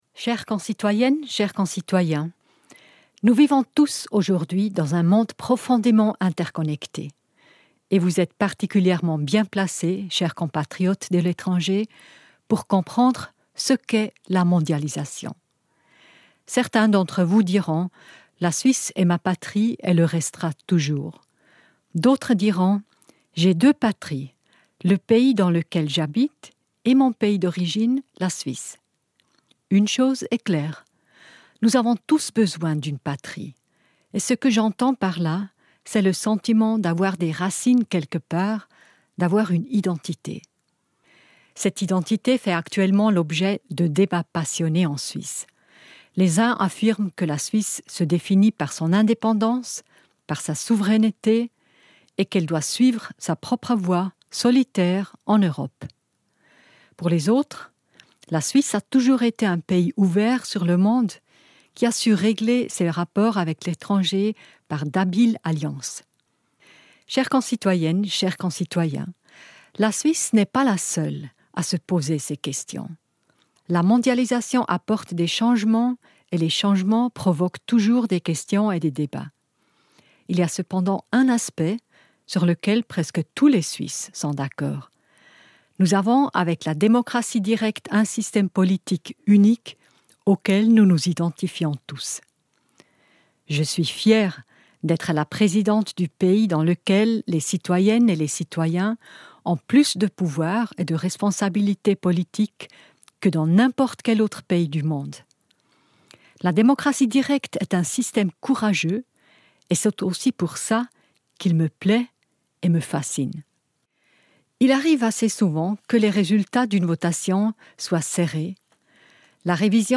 Comme il est de tradition, la présidente de la Confédération Simonetta Sommaruga adresse un message aux Suisses expatriés.
1.-August-Rede der Bundespräsidentin